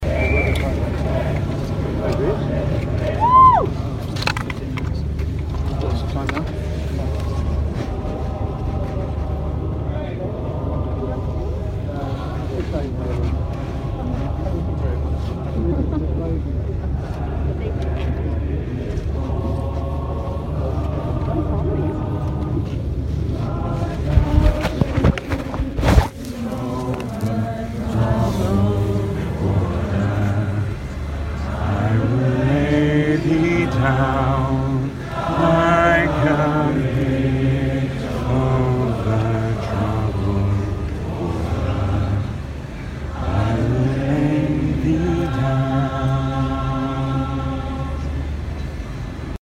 Yesterday evening's emotional chant followed by The Pink Singers with Bridge Over Troubled Water.